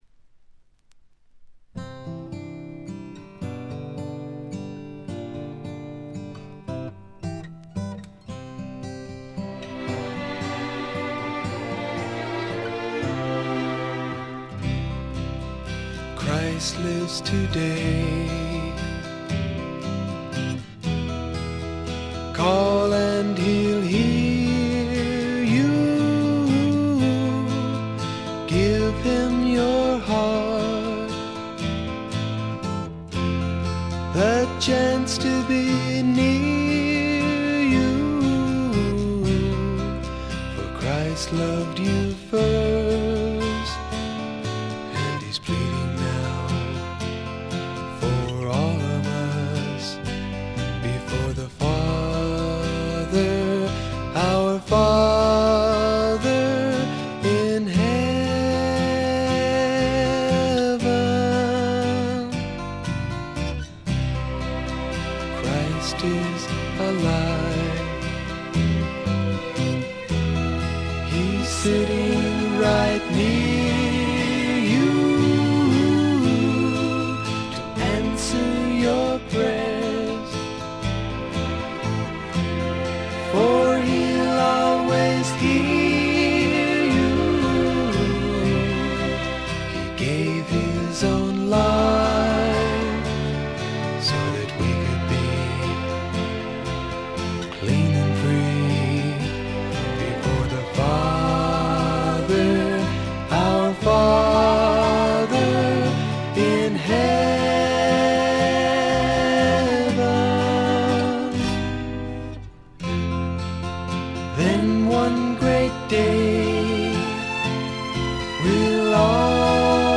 部分試聴ですがほとんどノイズ感無し。
クリスチャン・ミュージックの男女混成グループ
試聴曲は現品からの取り込み音源です。